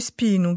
[spinu]